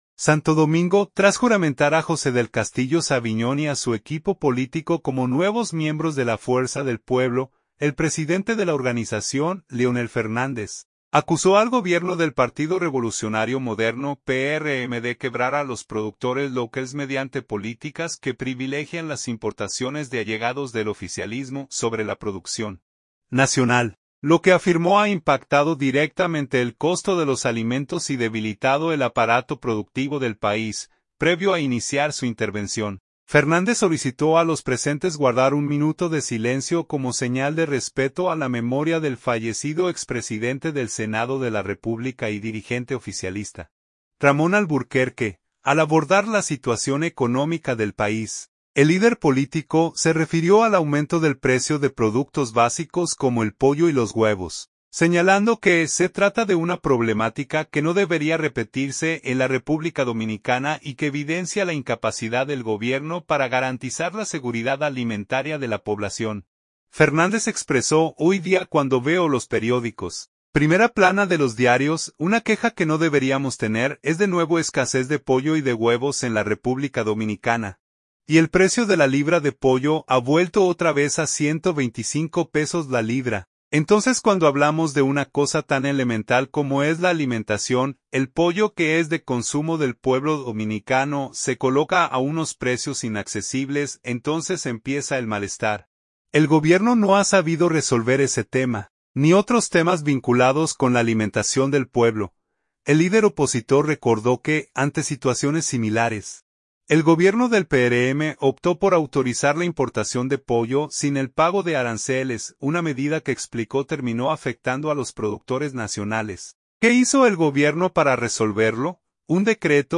Santo Domingo. – Tras juramentar a José del Castillo Saviñón y a su equipo político como nuevos miembros de la Fuerza del Pueblo, el presidente de la organización, Leonel Fernández, acusó al gobierno del Partido Revolucionario Moderno (PRM) de quebrar a los productores locales mediante políticas que privilegian las importaciones de allegados del oficialismo sobre la producción nacional, lo que afirmó ha impactado directamente el costo de los alimentos y debilitado el aparato productivo del país.
Previo a iniciar su intervención, Fernández solicitó a los presentes guardar un minuto de silencio como señal de respeto a la memoria del fallecido expresidente del Senado de la República y dirigente oficialista, Ramón Alburquerque.